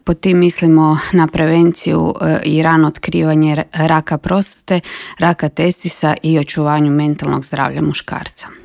u novom intervjuu Media servisa povodom Movembera - mjeseca podizanja svijesti o zdravstvenim problemima muškaraca.